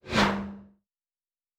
Fly By 03_2.wav